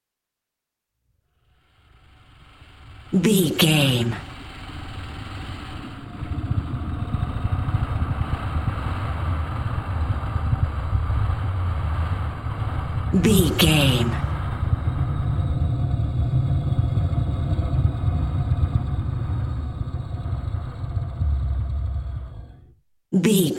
Wind evil slow growl air
Sound Effects
Atonal
ominous
haunting
eerie